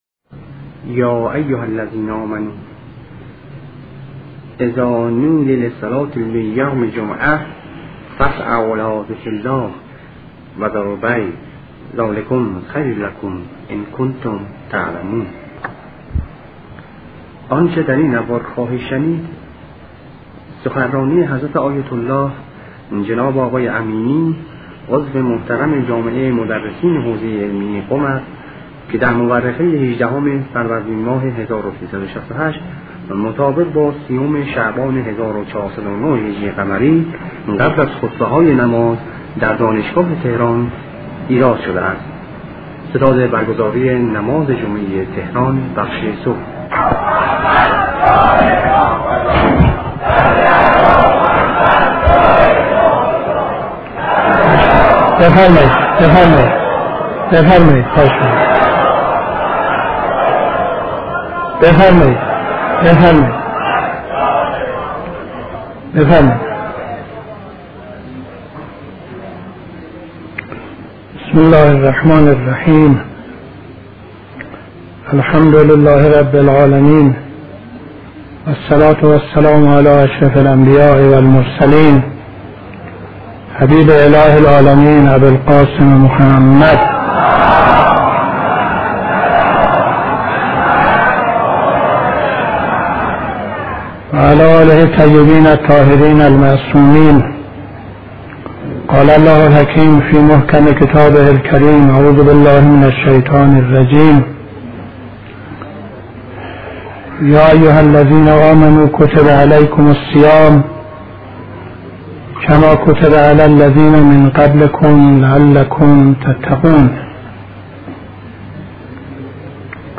قبل از خطبه‌های نماز جمعه تهران 18-01-68